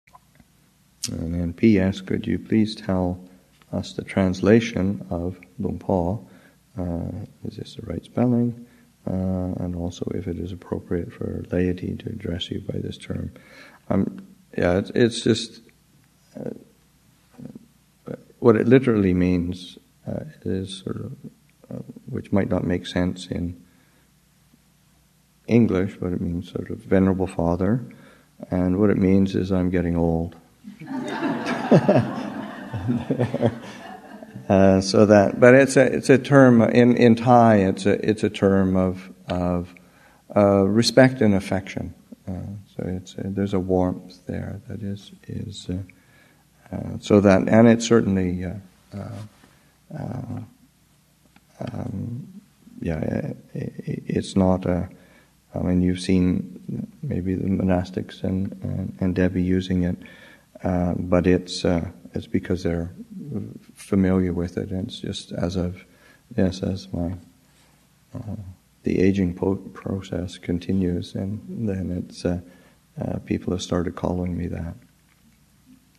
Abhayagiri Monastic Retreat 2013, Session 7 – Nov. 29, 2013